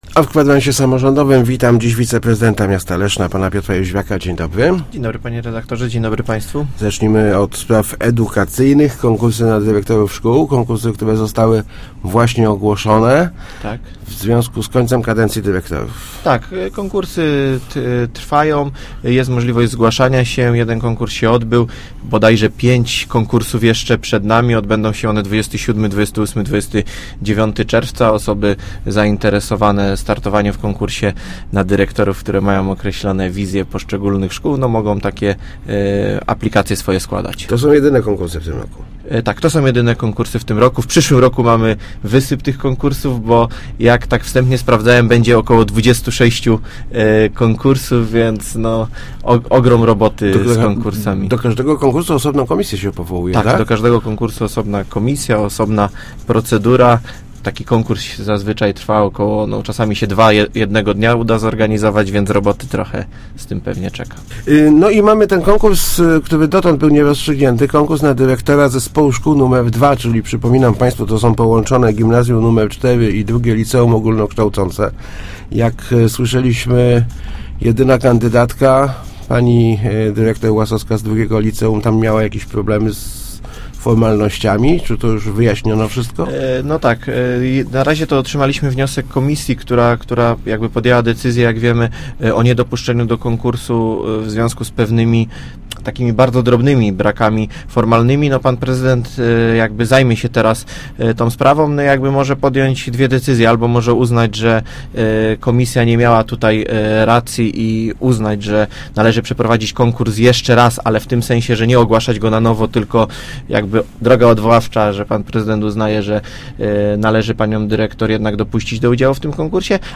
Ruszy� nabór wniosków w drugiej turze konkursu na finansowanie rozwoju sportu przez samorz�d Leszna. -Rozdysponujemy w niej 650 tysi�cy z�otych – powiedzia� w Kwadransie Samorz�dowym wiceprezydent Piotr Jó�wiak.